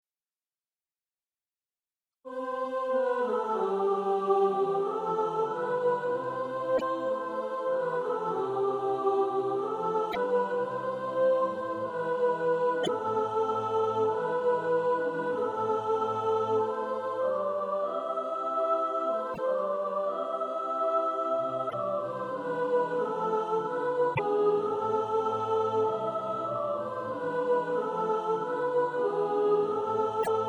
Soprano Track.
Practice then with the Chord quietly in the background.